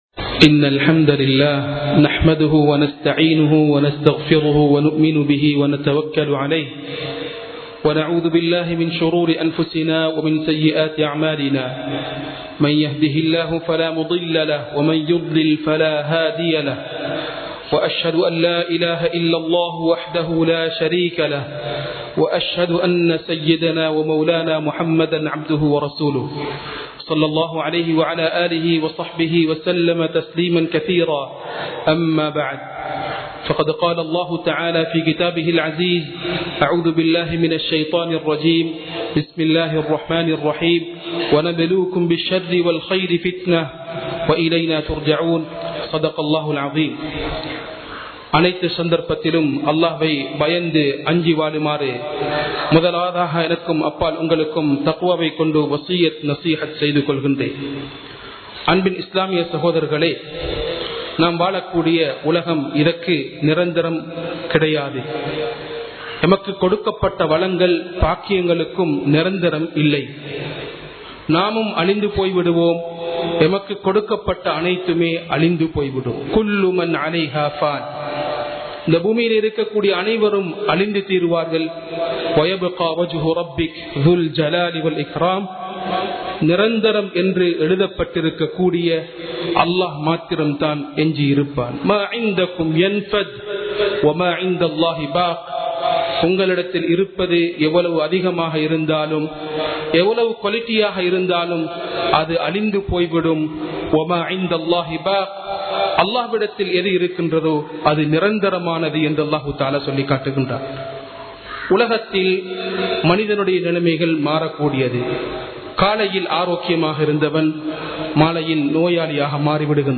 சோதனைகளின் போது | Audio Bayans | All Ceylon Muslim Youth Community | Addalaichenai
Majma Ul Khairah Jumua Masjith (Nimal Road)